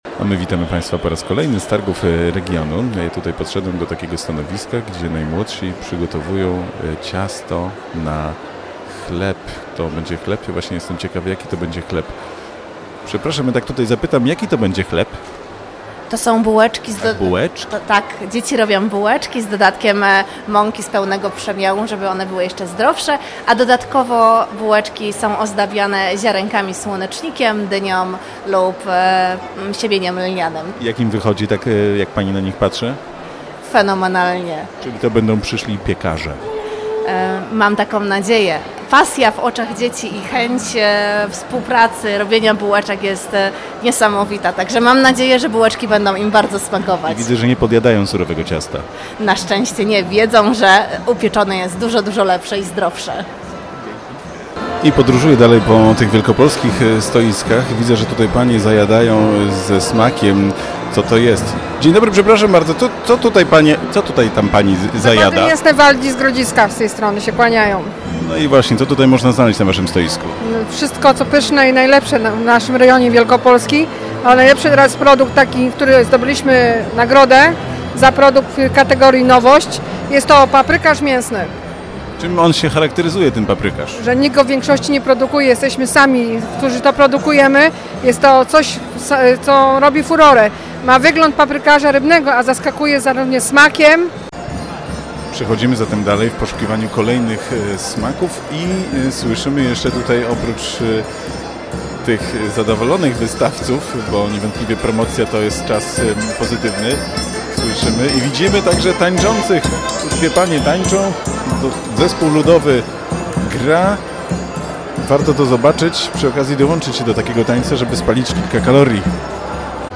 O idei targów oraz o zmianie, jaka nastąpiła w nastawieniu konsumentów do regionalnych produktów, mówił w wywiadzie marszałek Marek Woźniak.